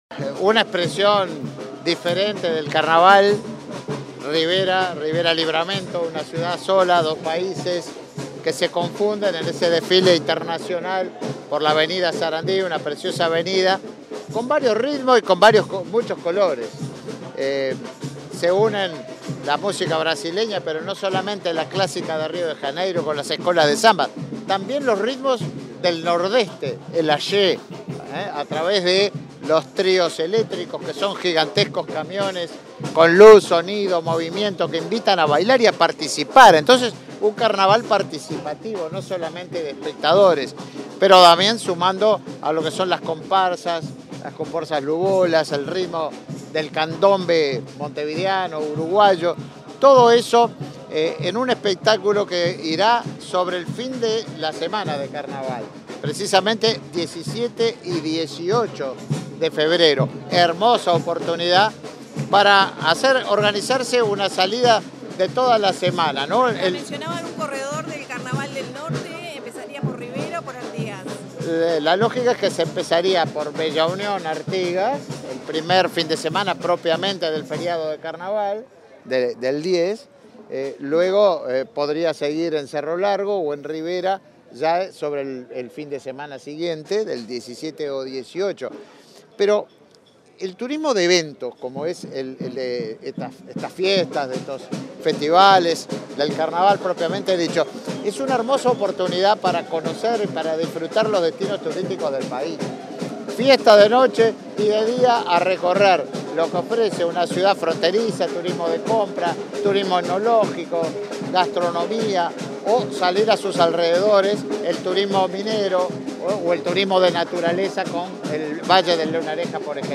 Tras el lanzamiento del Carnaval de Rivera, este 5 de febrero, el ministro de Turismo, Tabaré Viera, realizó declaraciones a la prensa.